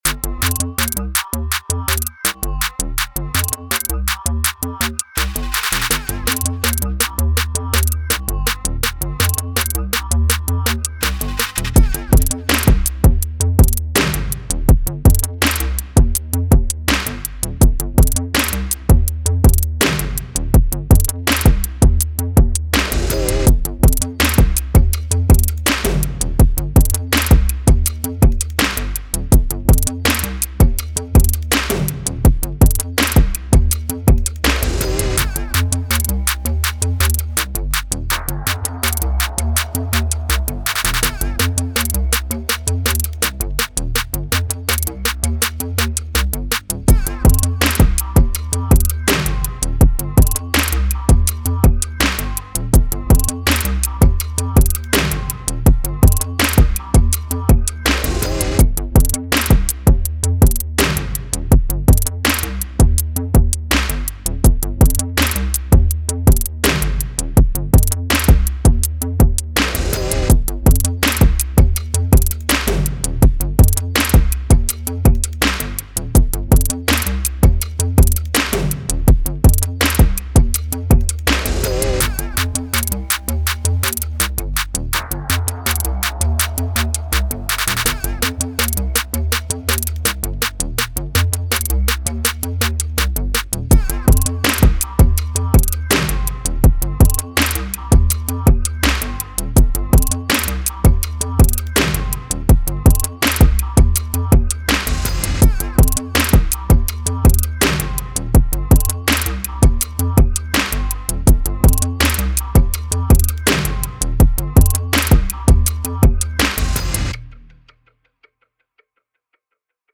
Hip Hop
Eb Maj